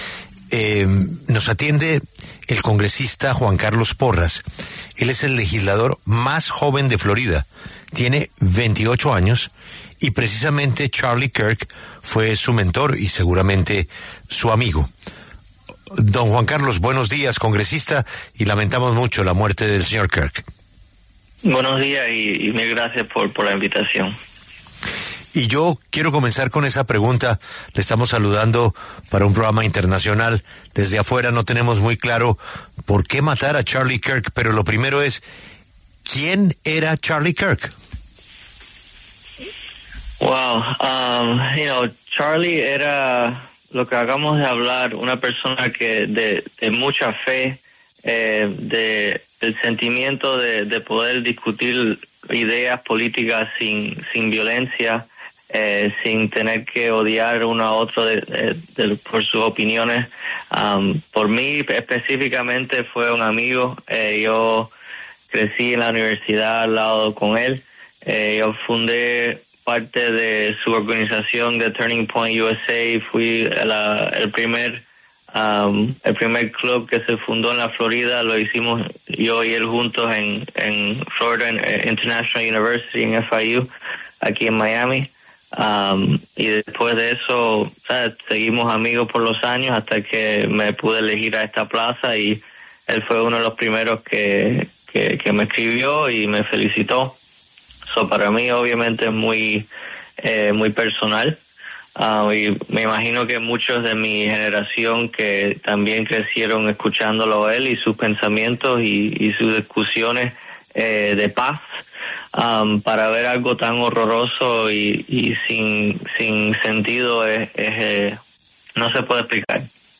El congresista republicano Juan Carlos Porras conversó con La W sobre Charlie Kirk, quien fue su amigo cercano, destacando su trabajo para que los jóvenes se interesaran en la política.